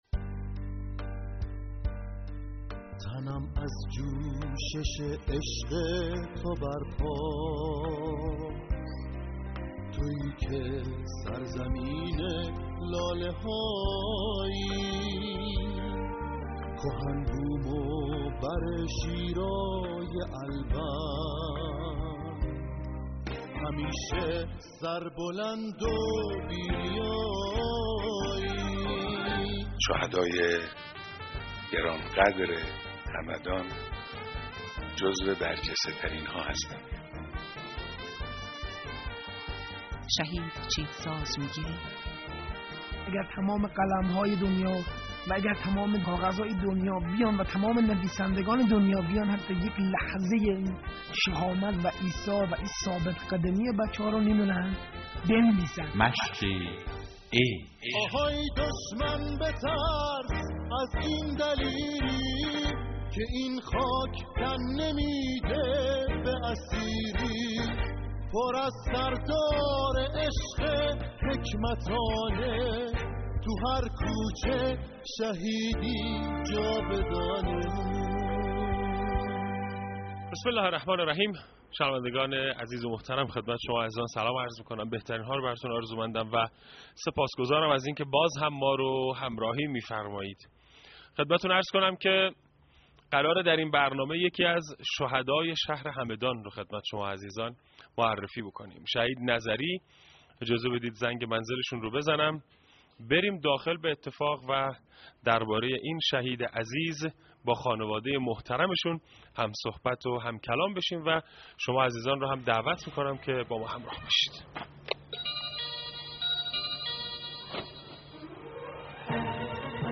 صوت: گفتگو